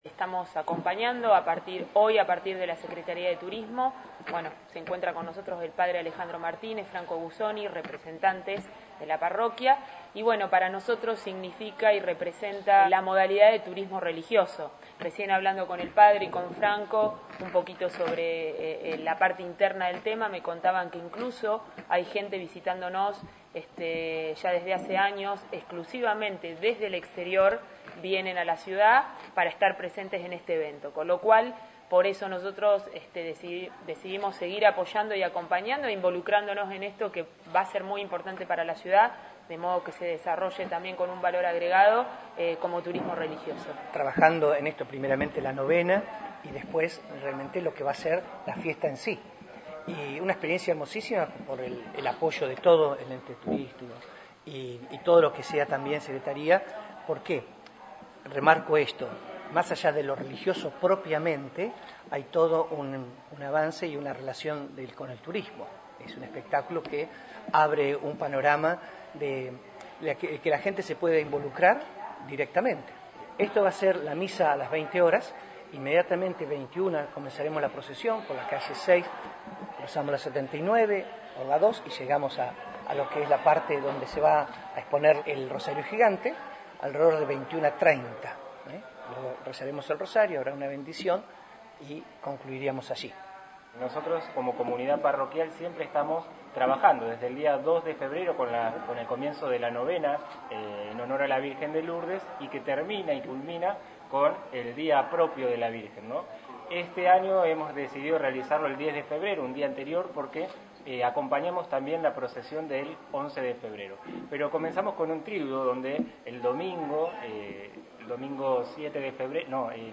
En conferencia de prensa, esta mañana la secretaria de Turismo de la comuna, Andrea Sabatini, presentó una nueva realización del Rosario Gigante Iluminado, una actividad que viene realizando la Parroquia Nuestra Señora de Lourdes desde hace trece años ininterrumpidamente en el marco de la Fiesta Patronal.